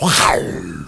growl.wav